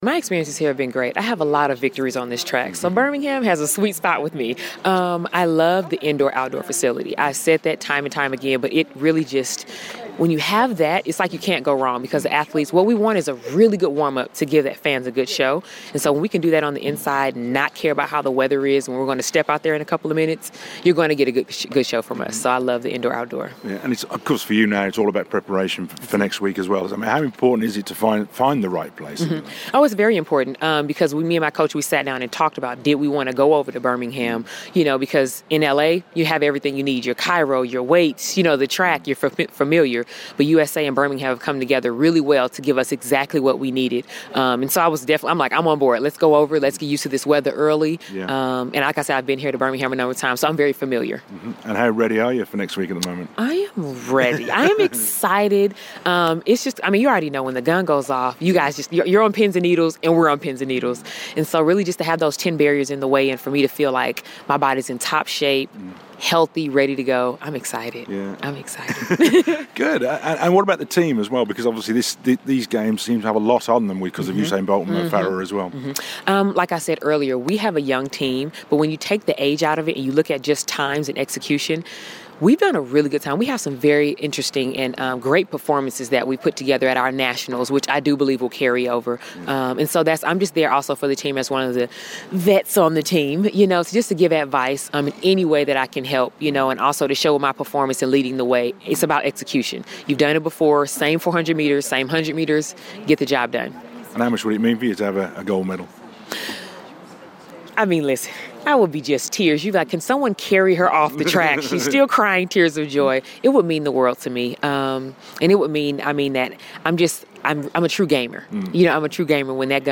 LISTEN: The USA track and field team are in Birmingham preparing for next weeks World Championships in London. Hurdler Dawn Harper Nelson talks about the city and her preparations.